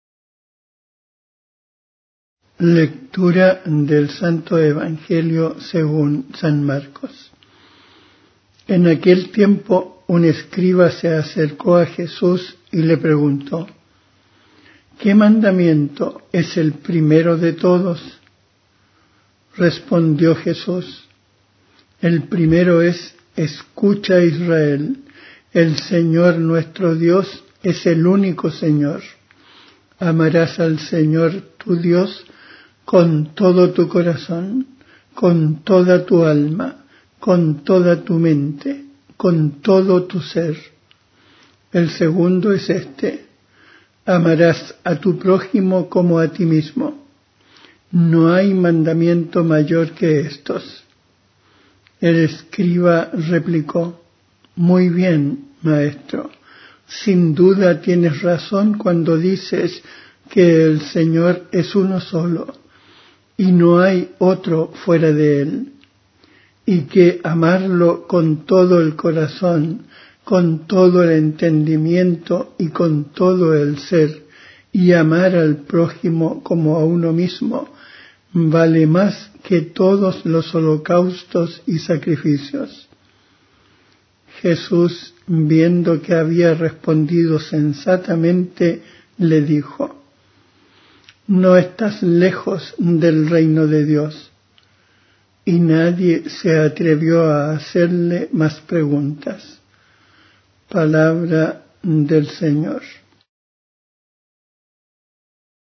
Evangelio en audio.
Musica di sottofondo